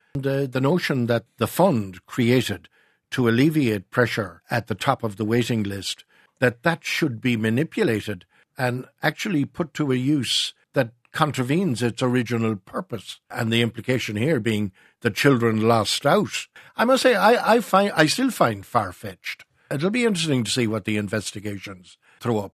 Former Labour Government Minister Pat Rabbitte says, he can’t believe what is being alleged.